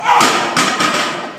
Clank